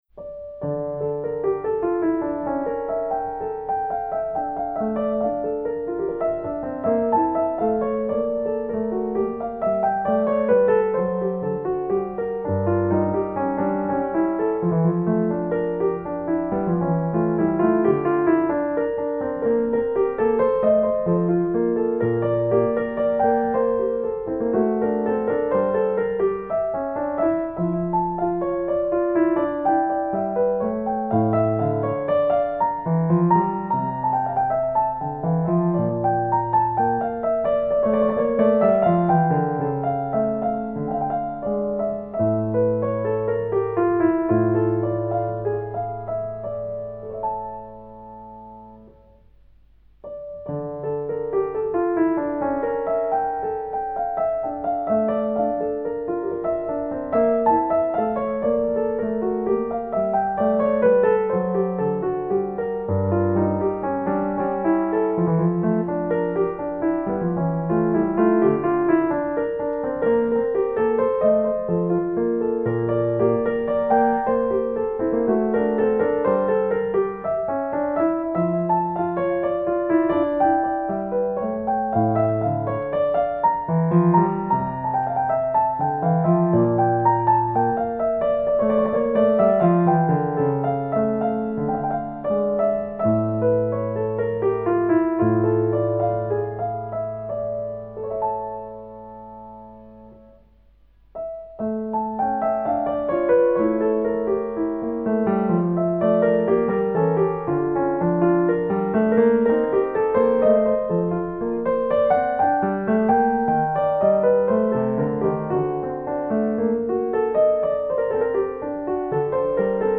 J.S.Bach_French_Suite_BWV_812_D_Minor_1_Allemande.mp3